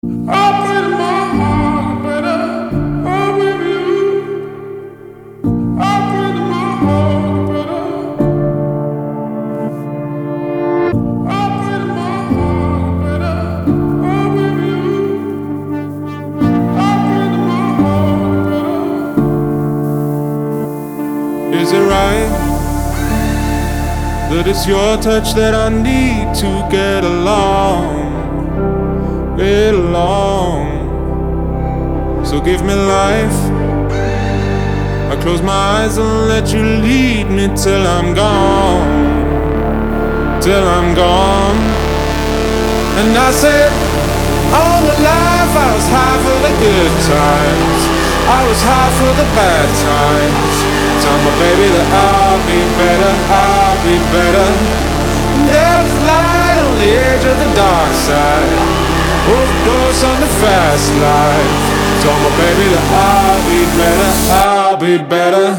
• Качество: 224, Stereo
мужской голос
dance
Electronic
спокойные
пианино
Bass